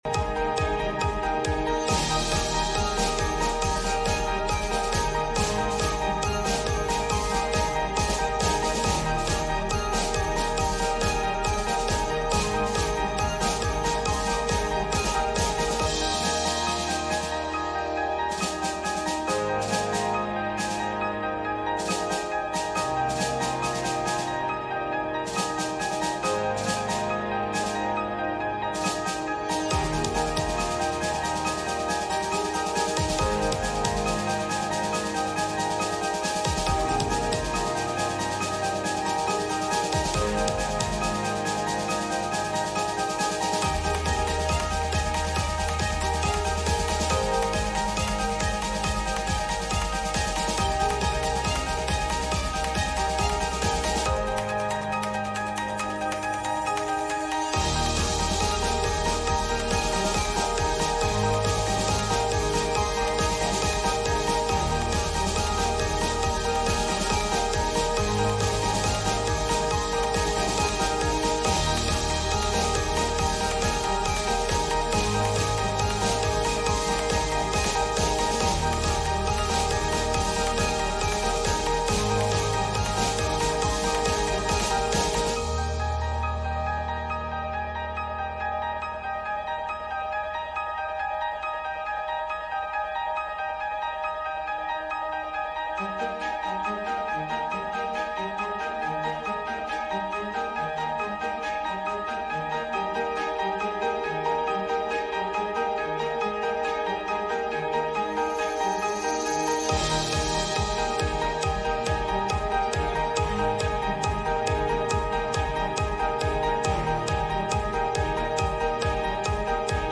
Youth Service W/ First Baptist Tillmans Corner
Service Type: Midweek Meeting